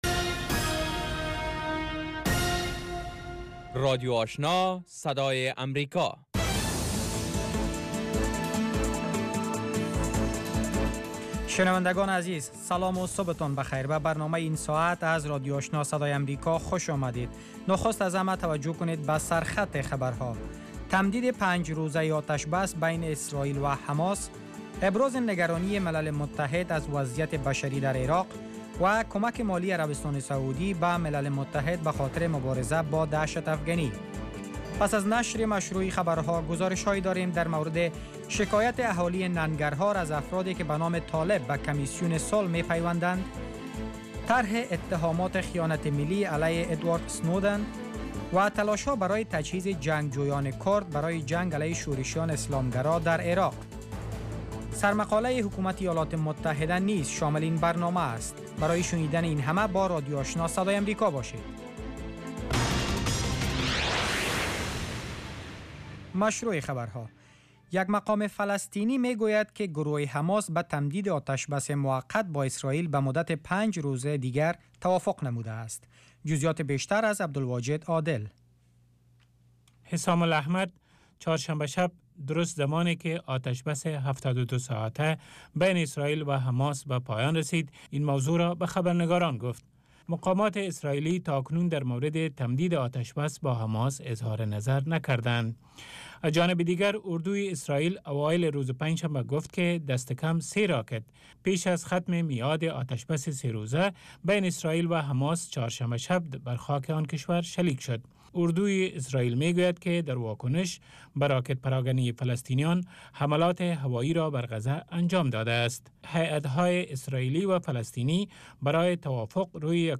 برنامه خبری صبح